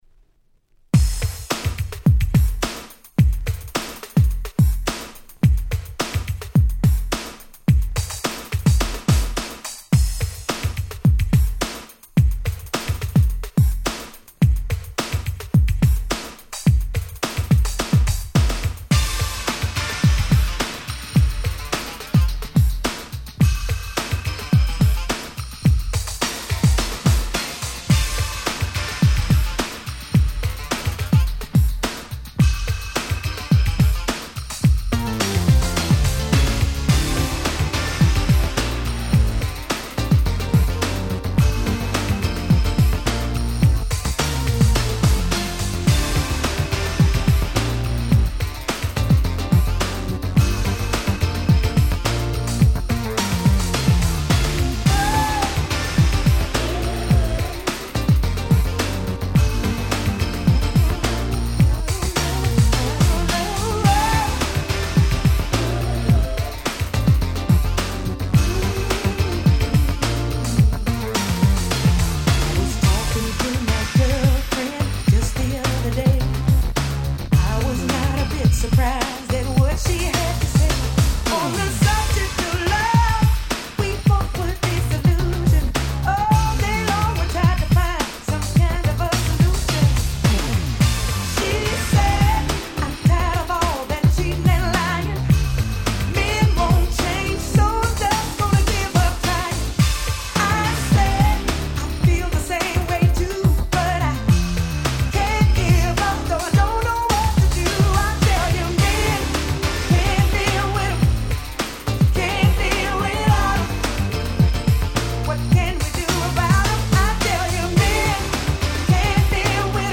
91' Nice R&B/New Jack Swing !!
あまり話題に上がる事がないのがウソの様な、歌物好きにはど真ん中確実の踊れる1曲！